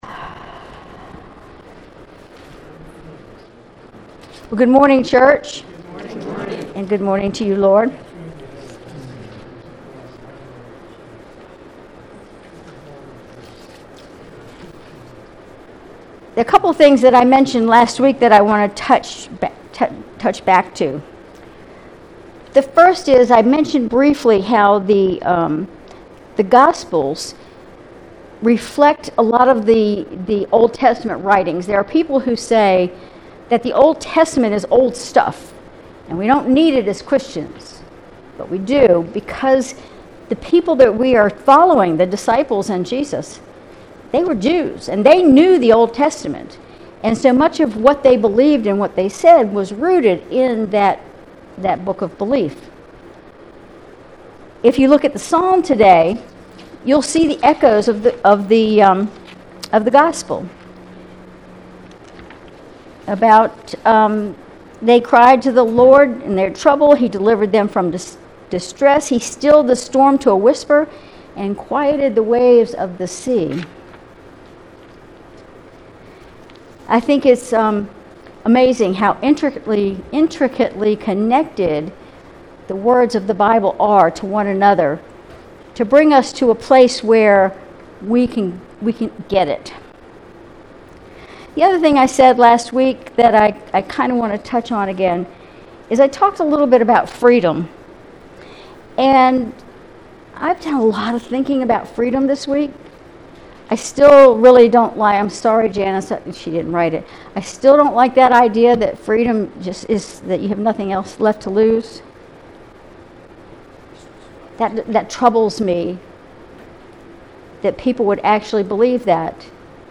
Sermon June 23, 2024